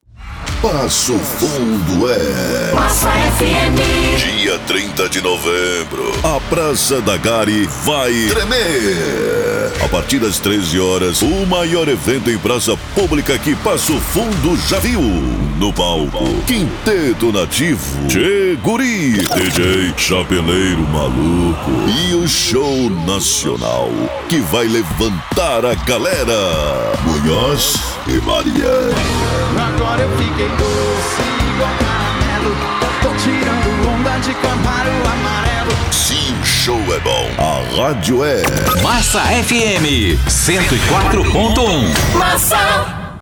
Spot - Show Massa Munhoz e Mariano 001: